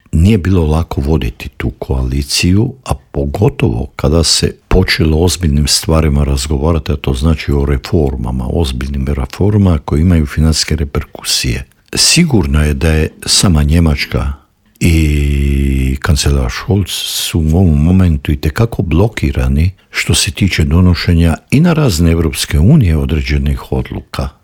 ZAGREB - Dinamična politička zbivanja u svijetu tema su i novog Intervjua Media servisa. S bivšim ministrom vanjskih poslova Matom Granićem, prošli smo atlas tražeći odgovore na to kako će Trumpova vladavina odjeknuti izvan granica SAD-a, a razgovarali smo i o krizi vlasti u Njemačkoj.